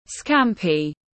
Món tôm biển rán tiếng anh gọi là scampi, phiên âm tiếng anh đọc là /ˈskæm.pi/